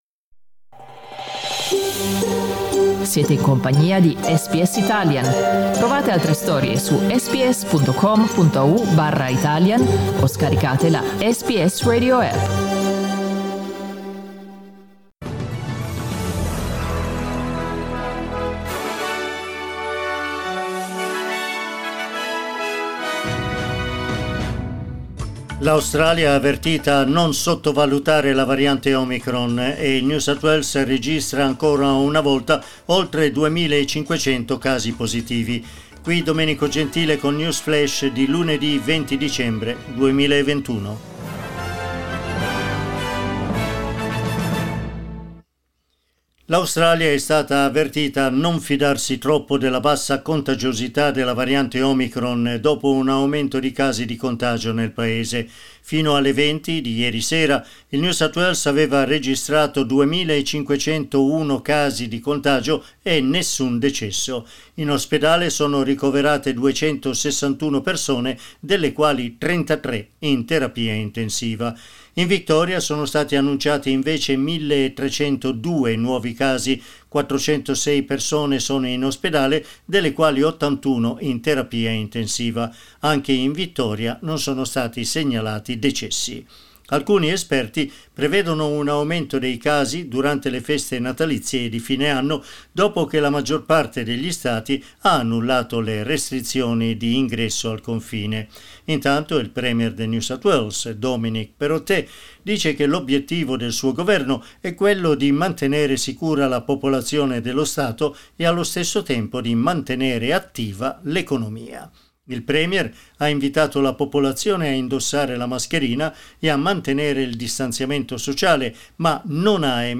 News flash lunedì 20 dicembre 2021